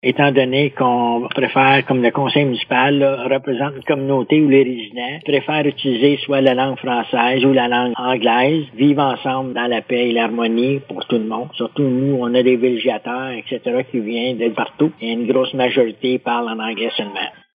Le maire de Kazabazua, Robert Bergeron, explique les raisons qui motivent la municipalité à maintenir son statut bilingue :